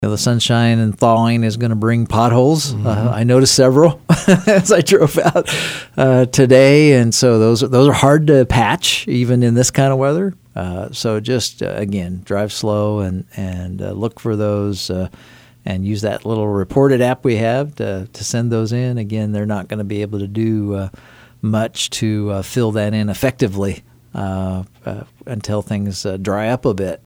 City manager Ron Fehr: